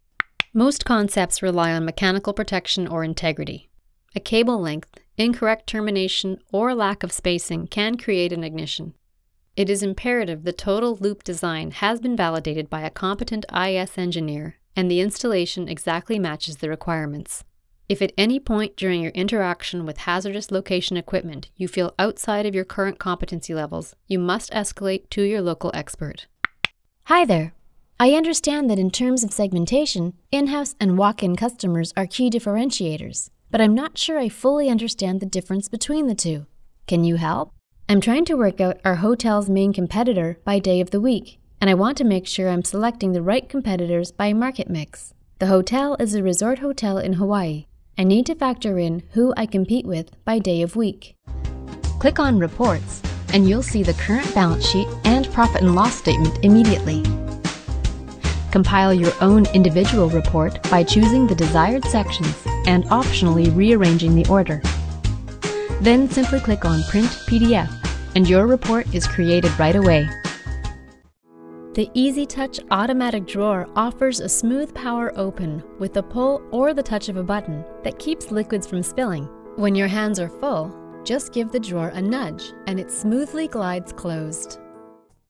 Female
English (North American)
E-Learning
Learning, Training, Education
0129E-Learning_demo.mp3